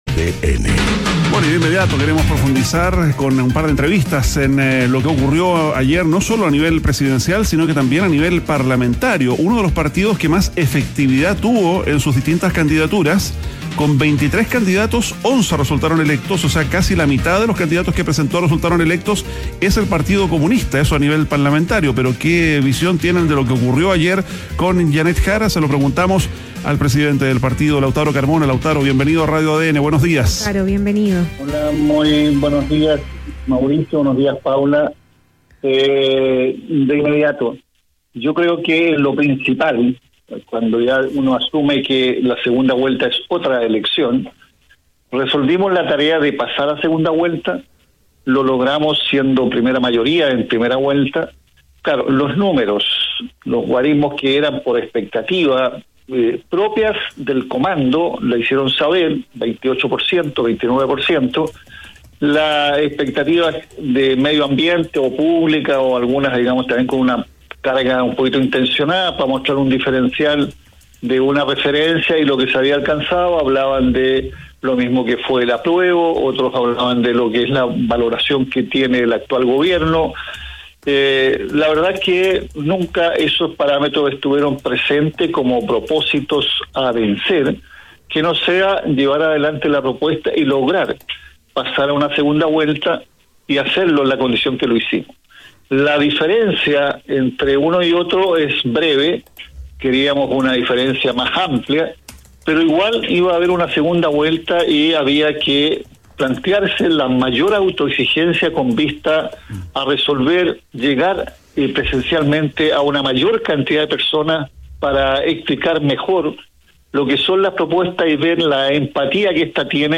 ADN Hoy - Entrevista Lautaro Carmona, presidente del Partido Comunista